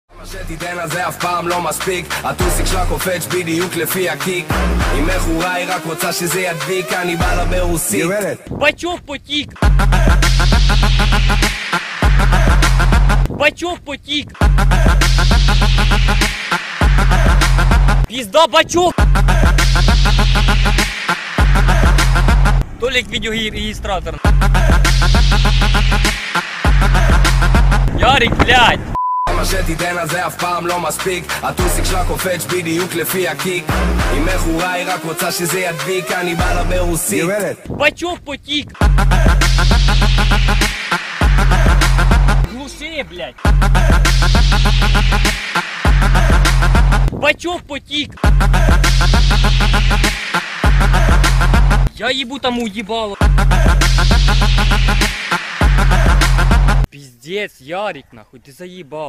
• Качество: 128, Stereo
веселые
Trap
клубняк
mash up